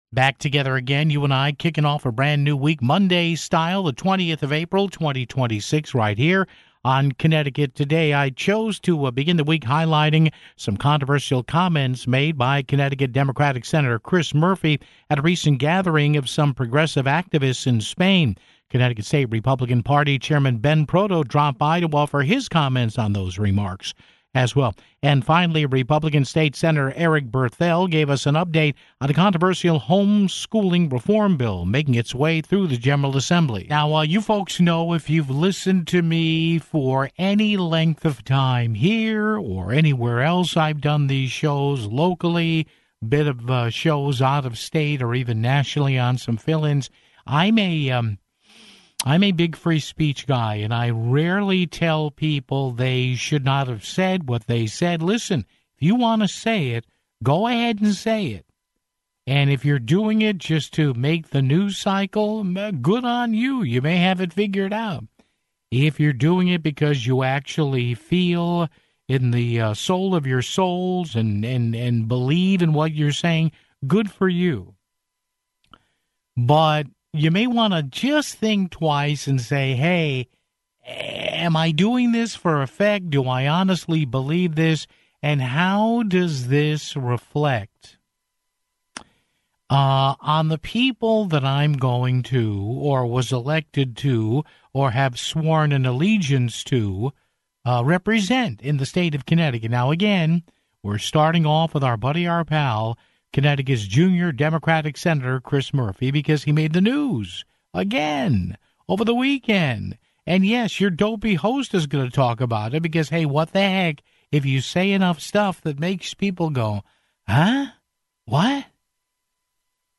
Finally, GOP State Sen. Eric Berthel gave us an update on a controversial homeschooling reform bill at the State Capitol (22:55)